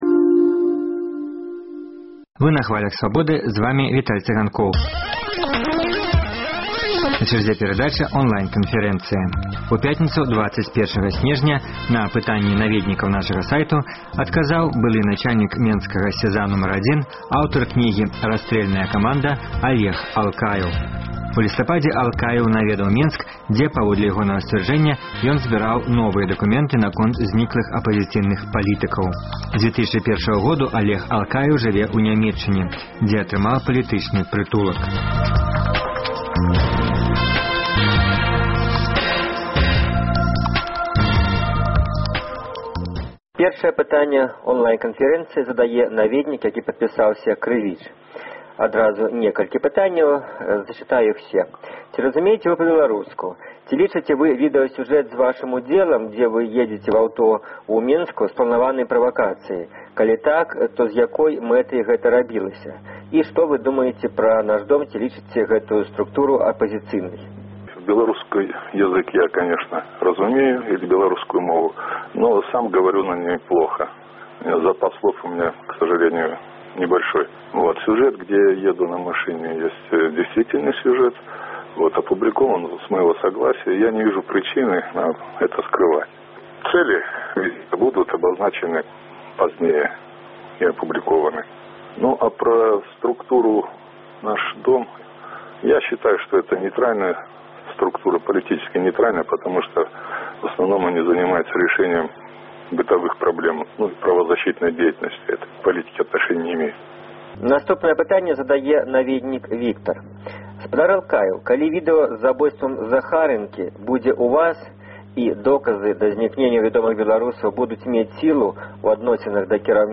Онлайн-канфэрэнцыя